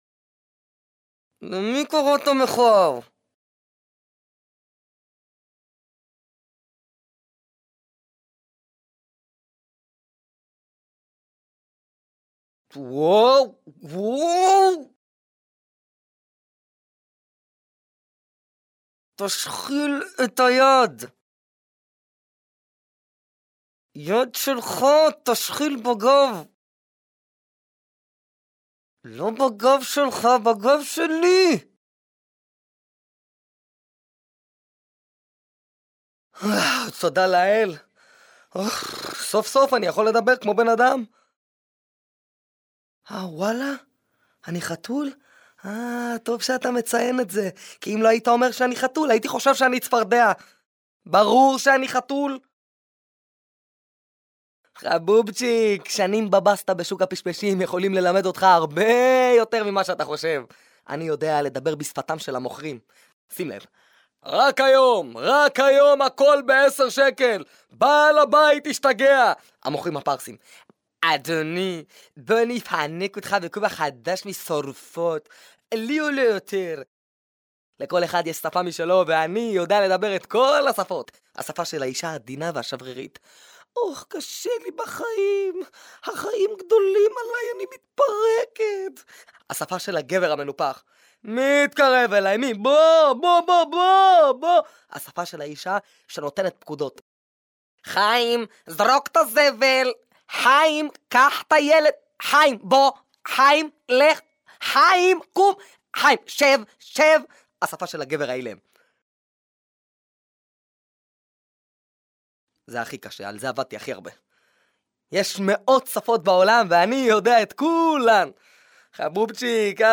דיבוב החתול במגפיים